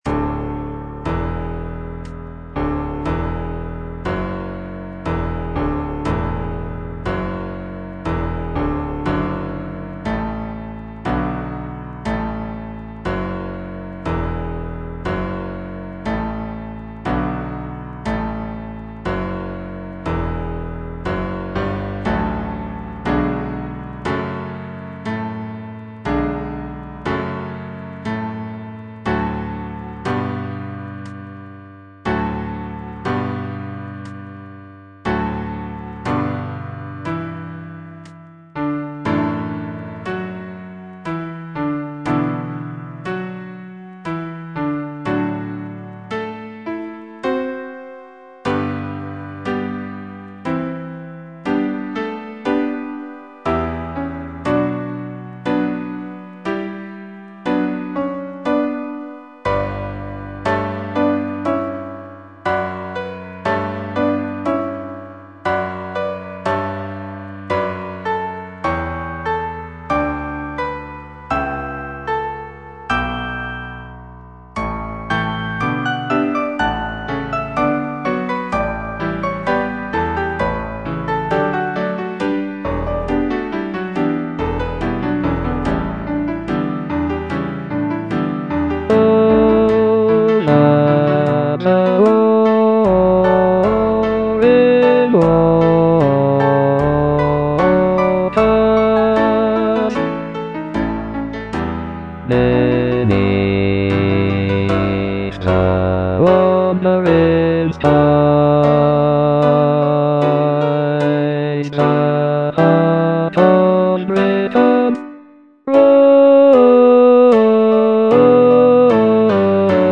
C.H.H. PARRY - THE CHIVALRY OF THE SEA Over the warring waters - Bass (Voice with metronome) Ads stop: auto-stop